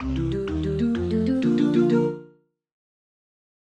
game_win.mp3